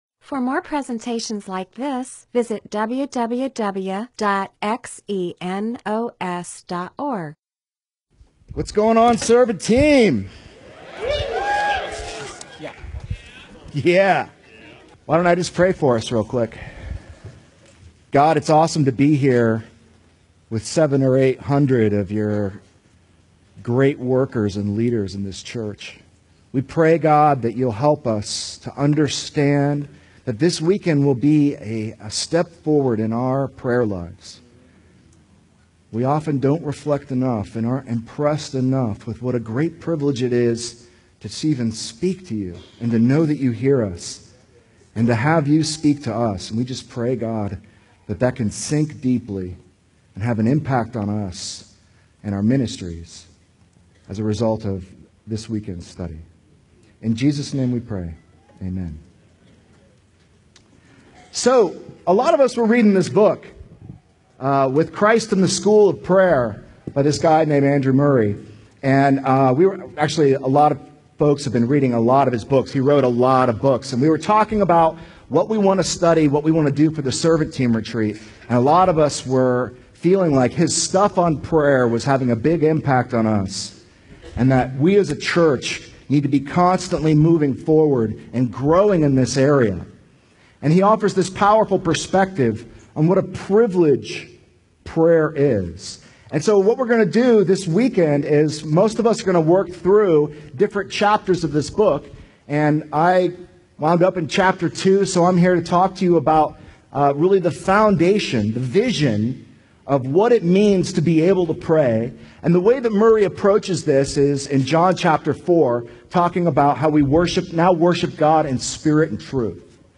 MP4/M4A audio recording of a Bible teaching/sermon/presentation about John 4:21-24.